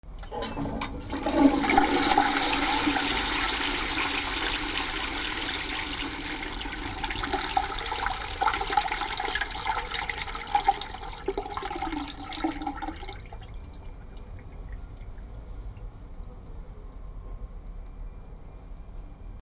トイレ 水を流す
/ M｜他分類 / L05 ｜家具・収納・設備 / トイレ
『ゴジャー』